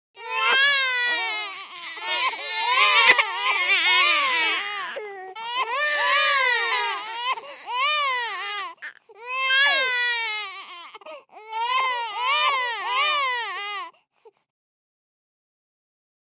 Детский плач, несколько детей
• Категория: Плач и слезы ребенка
• Качество: Высокое